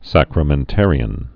(săkrə-mĕn-târē-ən)